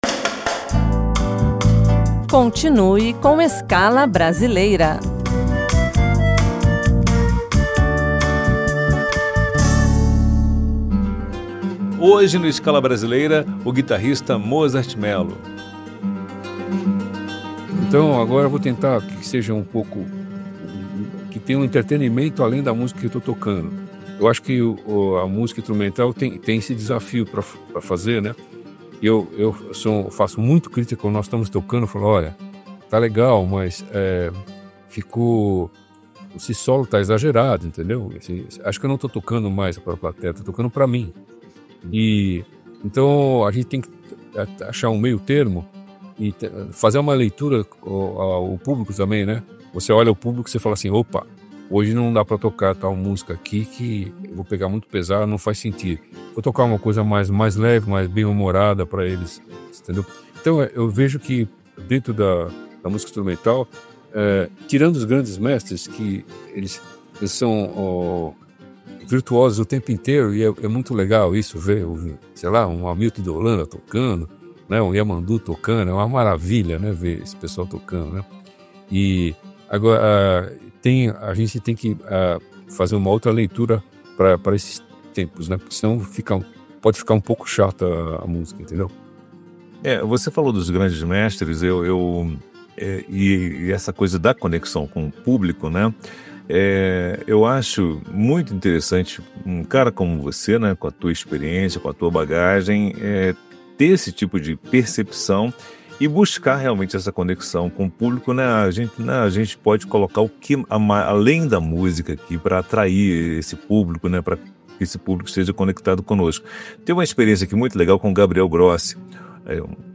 Jazz
INSTRUMENTAL